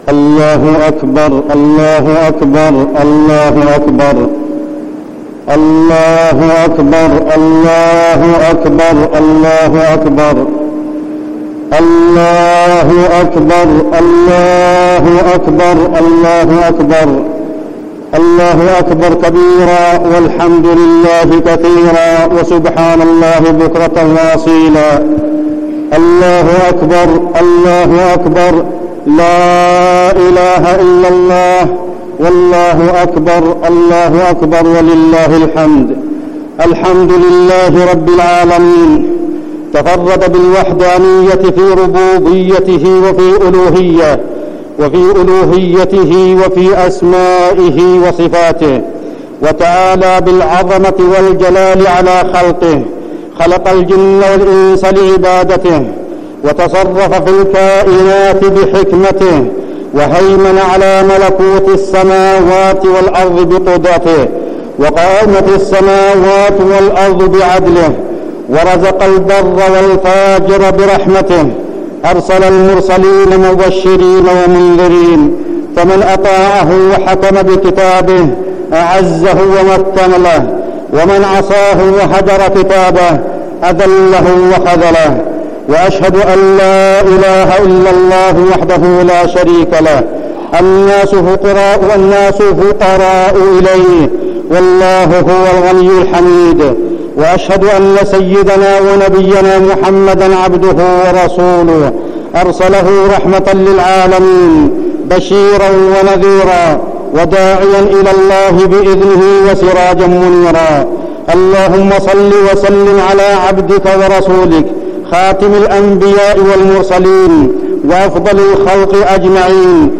تاريخ النشر ١٠ ذو الحجة ١٤٠٩ هـ المكان: المسجد النبوي الشيخ
خطبة عيد الأضحى - المدينة